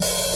Closed Hats
ASZ_OPENHAT.wav